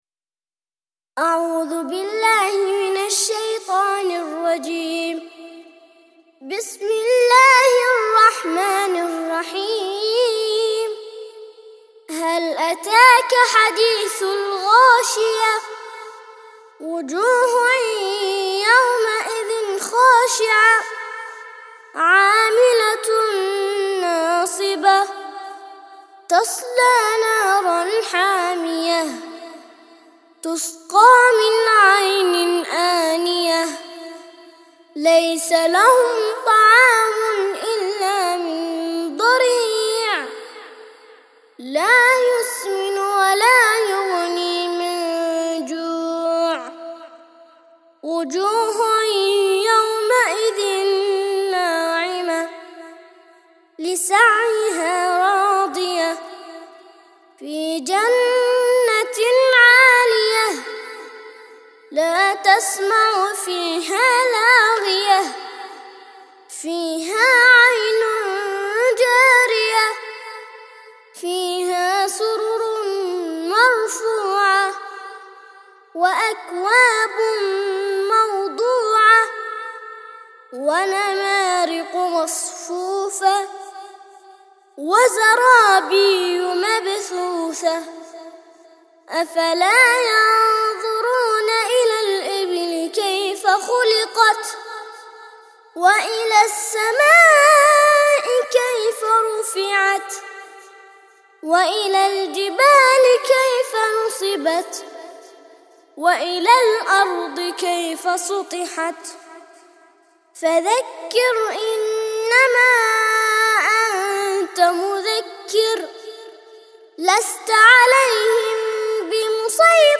88- سورة الغاشية - ترتيل سورة الغاشية للأطفال لحفظ الملف في مجلد خاص اضغط بالزر الأيمن هنا ثم اختر (حفظ الهدف باسم - Save Target As) واختر المكان المناسب